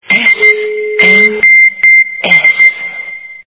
» Звуки » звуки для СМС » Азбука морзе - СМС
При прослушивании Азбука морзе - СМС качество понижено и присутствуют гудки.